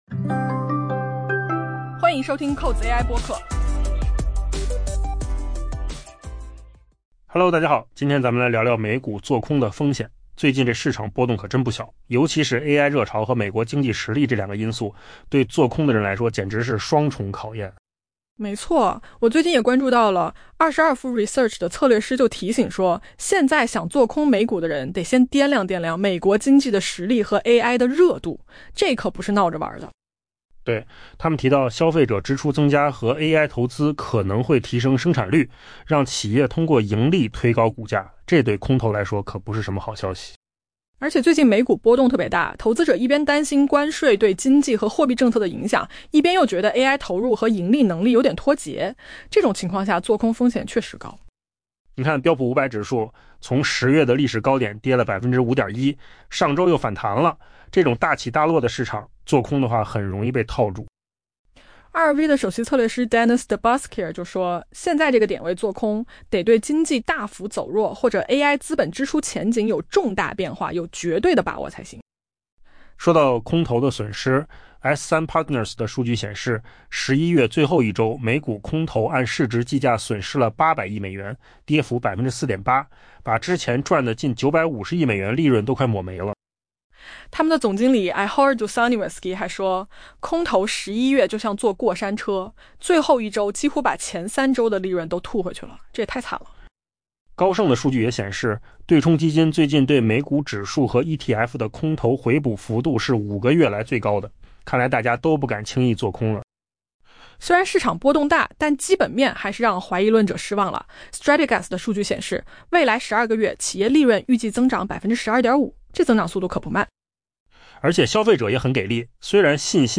AI 播客：换个方式听新闻 下载 mp3 音频由扣子空间生成 22V Research 的策略师表示， 本月任何想要做空美股的人，最好先掂量一下美国经济的实力以及对人工智能持续高涨的热情。